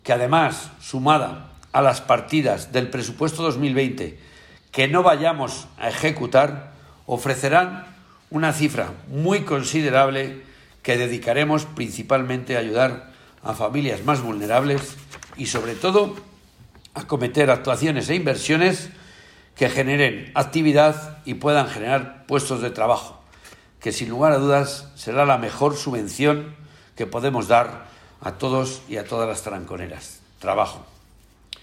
El Ayuntamiento de Tarancón cerró 2019 sin deuda y con un superávit de más de 4 millones de euros. Así lo ha dado a conocer el primer edil, José Manuel López Carrizo, durante una rueda de prensa en la que ha informado que el consistorio trasladará estos datos al Ministerio de Hacienda para que así figuren de manera oficial.